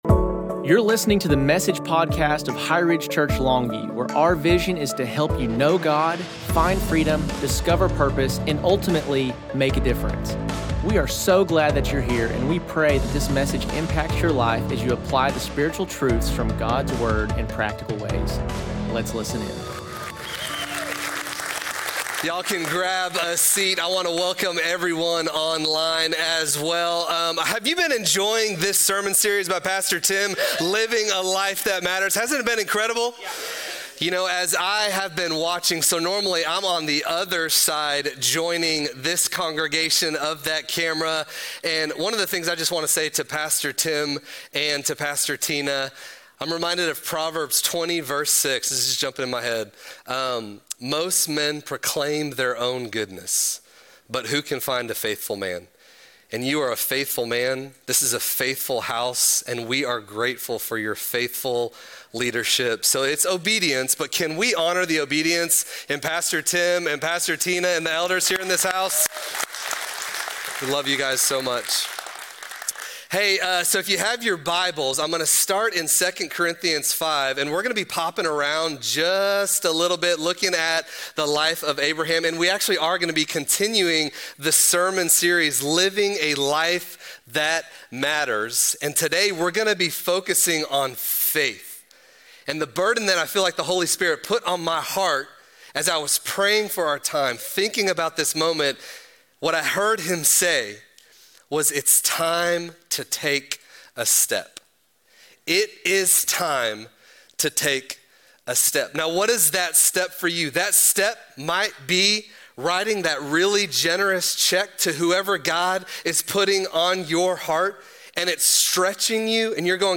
Message: A Life That Matters (Time To Take A Step)